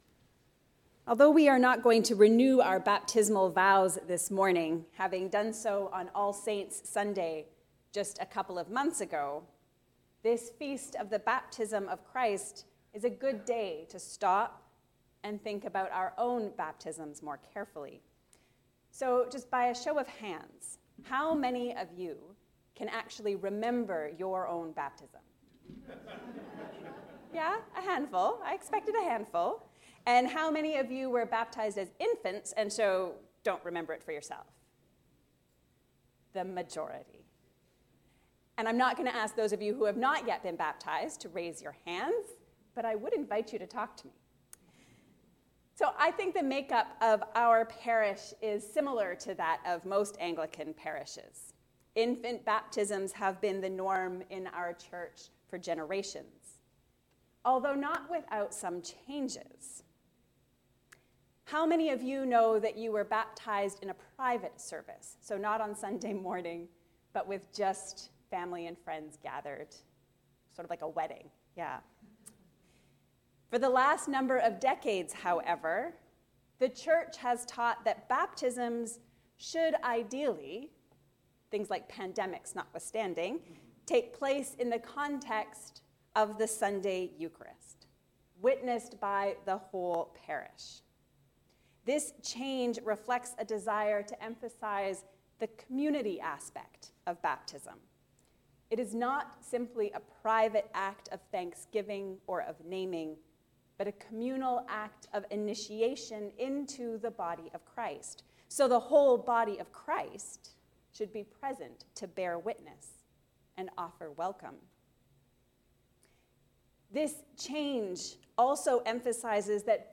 Baptism – where it all begins. A sermon for the Baptism of Jesus